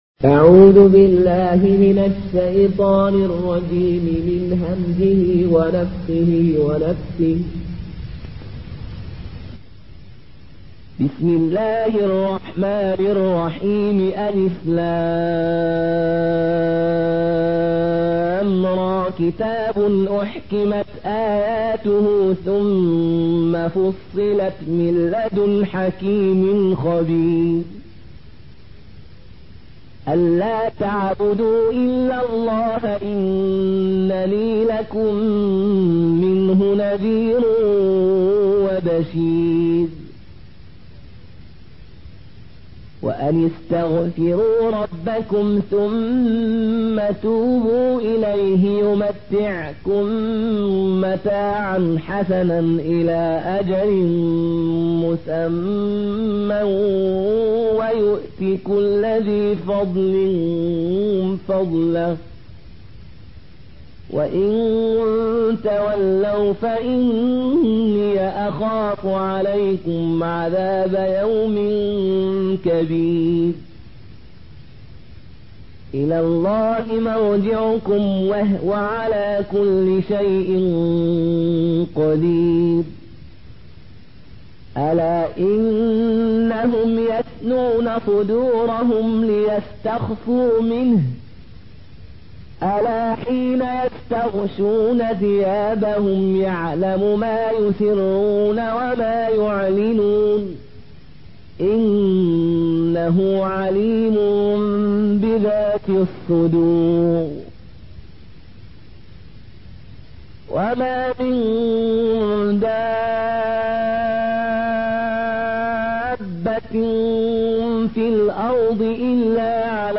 Une récitation touchante et belle des versets coraniques par la narration Qaloon An Nafi.
Murattal Qaloon An Nafi